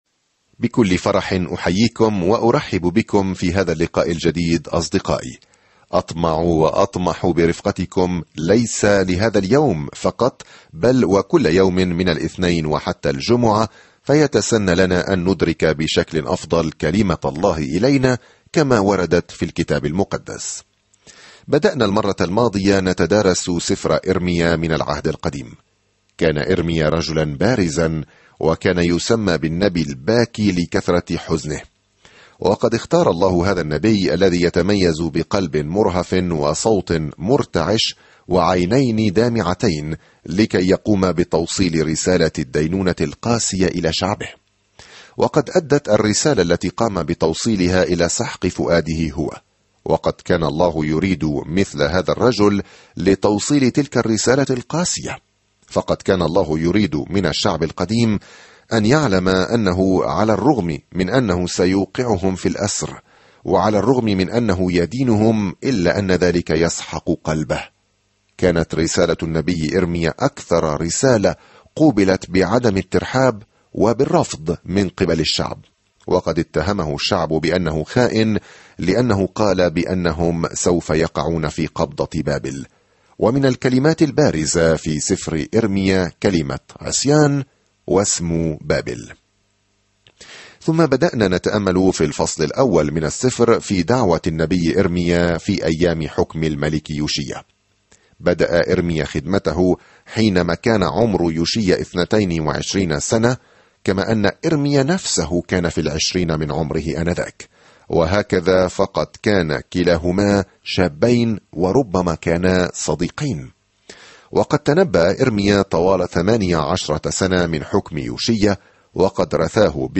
الكلمة إِرْمِيَا 4:1-19 إِرْمِيَا 1:2 يوم 1 ابدأ هذه الخطة يوم 3 عن هذه الخطة لقد اختار الله إرميا، الرجل الحنون، ليوصل رسالة قاسية، لكن الشعب لم يتقبل الرسالة جيدًا. سافر يوميًا عبر إرميا وأنت تستمع إلى الدراسة الصوتية وتقرأ آيات مختارة من كلمة الله.